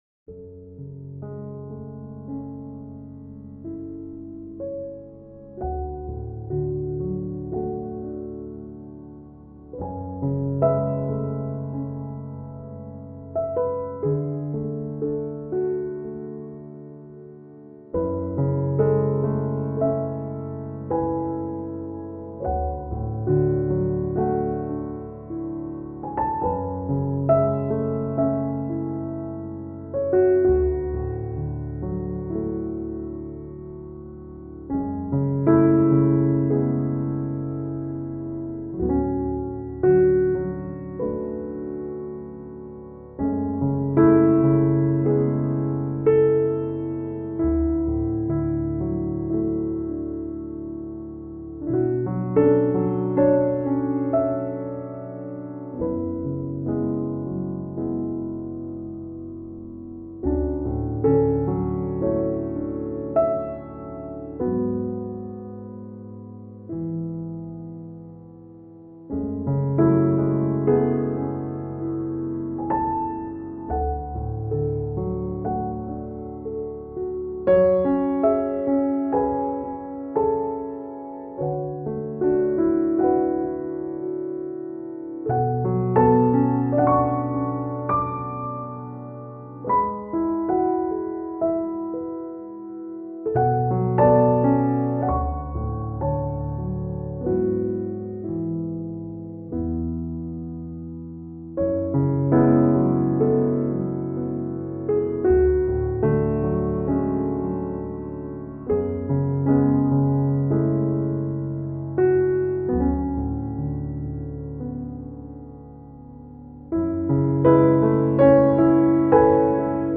آرامش بخش , پیانو , عصر جدید , موسیقی بی کلام